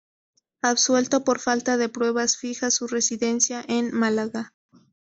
Pronounced as (IPA) /ˈfixa/